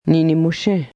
Some long vowels are pronounced with air flowing through the nose.